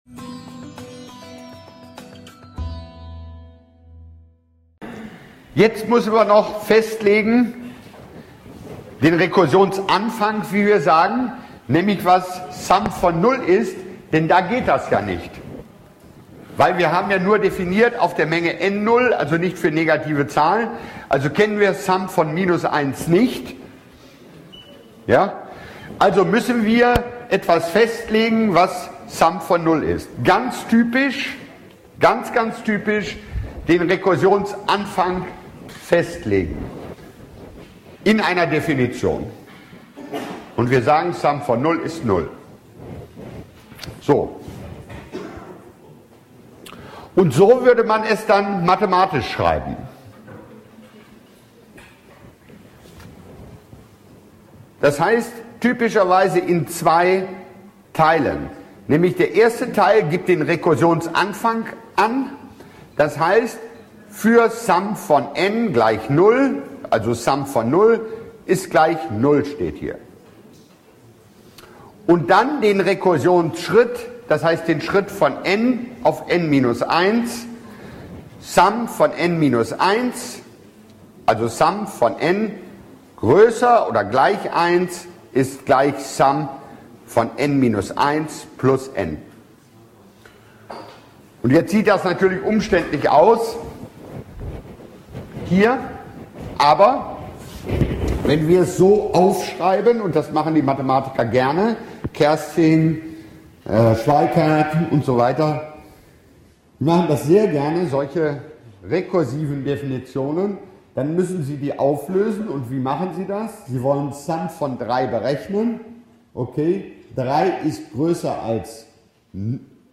Bockenheim Datum 27.01.2012 Beschreibung Der Ton klingt leider gruselig... Sorry!